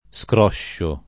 vai all'elenco alfabetico delle voci ingrandisci il carattere 100% rimpicciolisci il carattere stampa invia tramite posta elettronica codividi su Facebook scrosciare v.; scroscio [ S kr 0 ššo ], ‑sci — fut. scroscerò [ S kroššer 0+ ] — pop. tosc. strosciare : stroscio [ S tr 0 ššo ], -sci